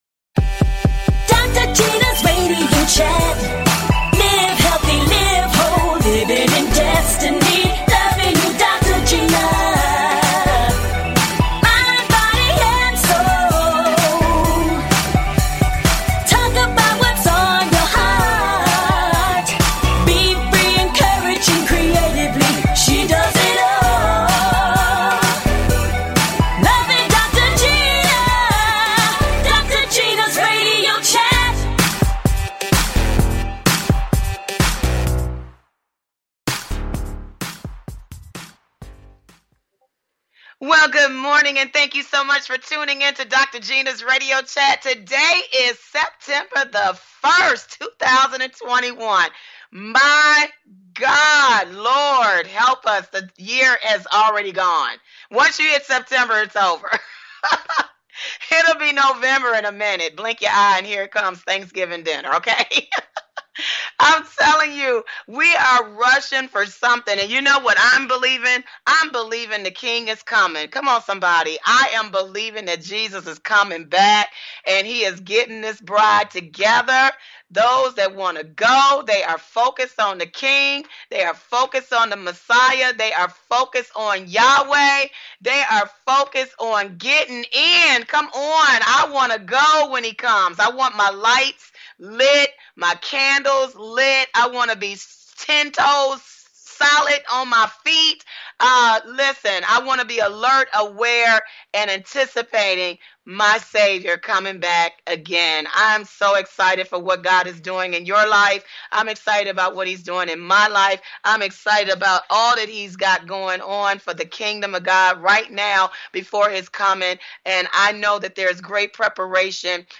Talk Show
Fun! Exciting! And full of laughter!